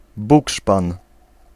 Ääntäminen
IPA : /bɒks/